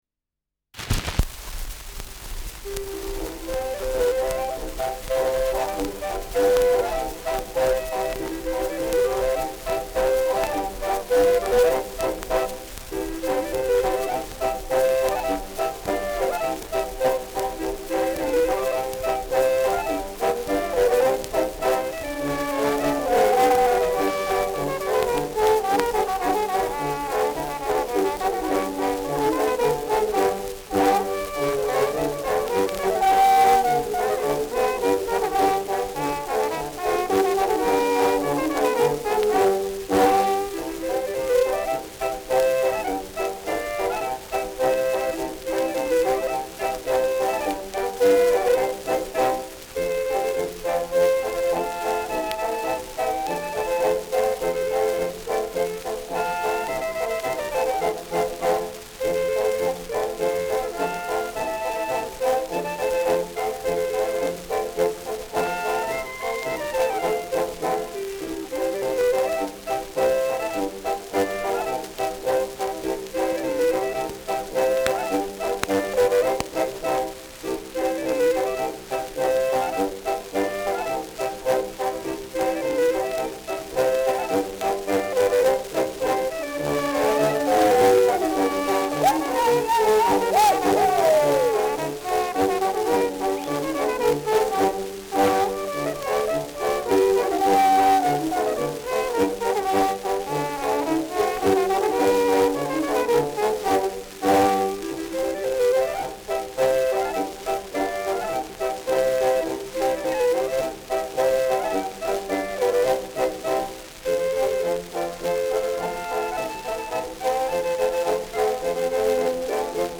Schellackplatte
ausgeprägtes Rauschen